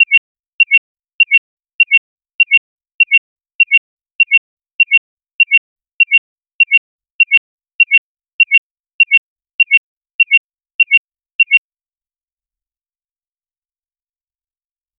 cat-meow--bleep-censor-62drickq.wav